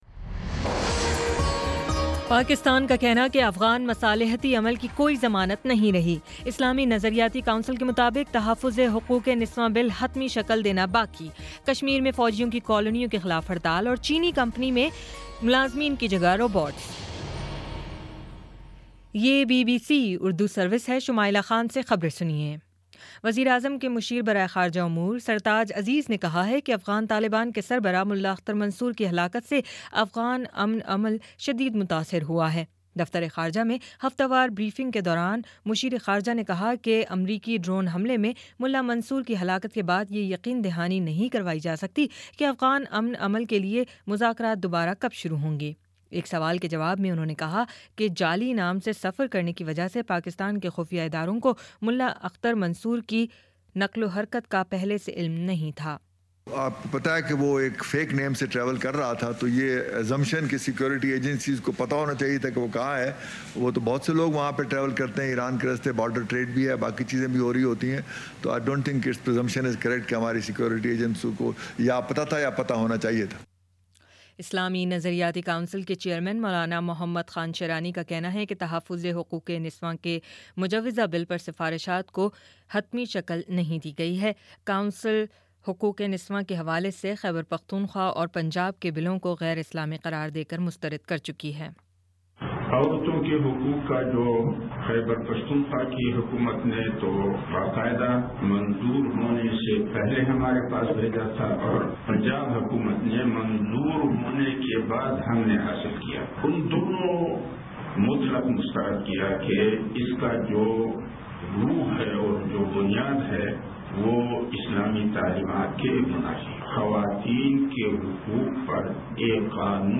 مئی 26 : شام چھ بجے کا نیوز بُلیٹن